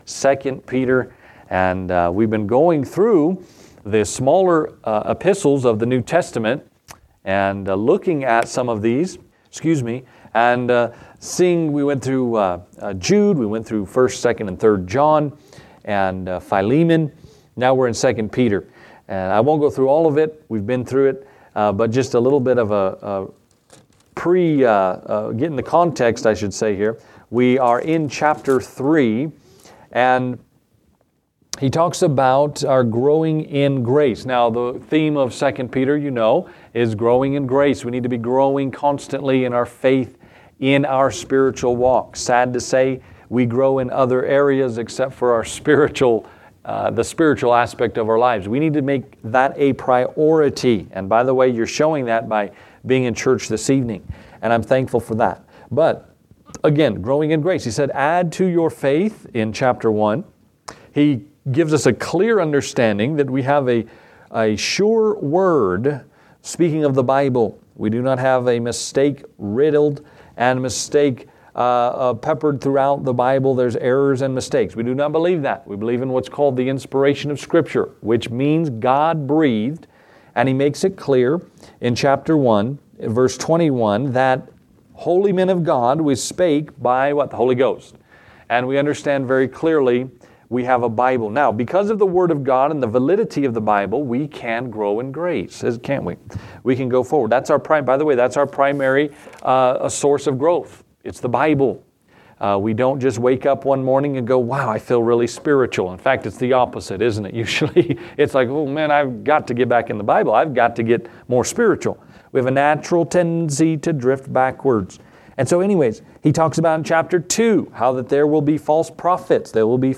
Gospel Message